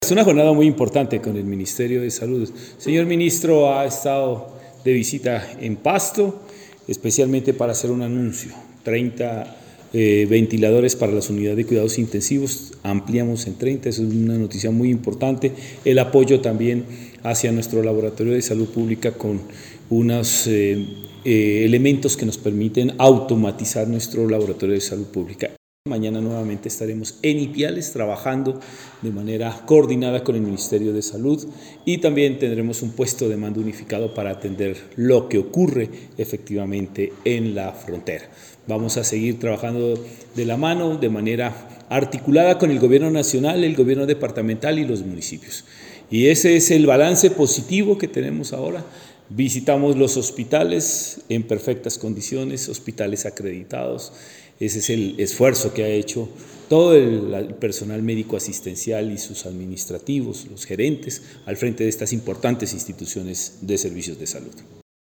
Compromisos-Minsalud-Gobernador-Jhon-Rojas-3.mp3